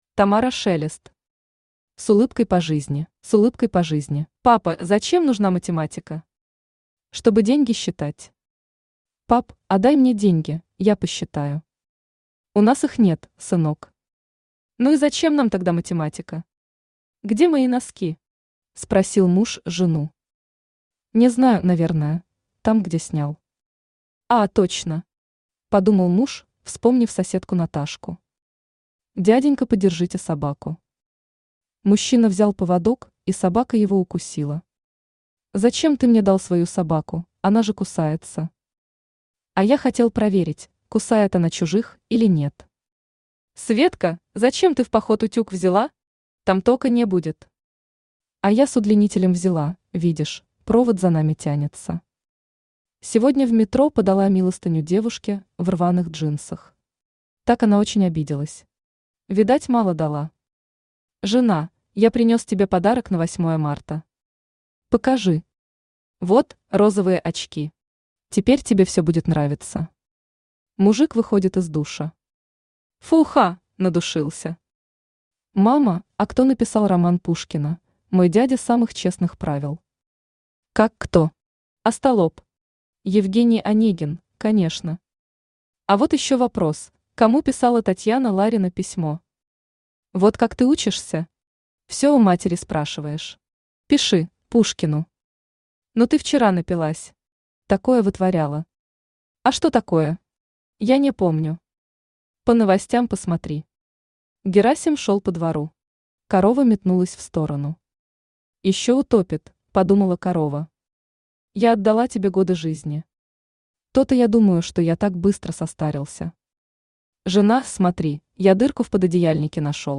Аудиокнига С улыбкой по жизни | Библиотека аудиокниг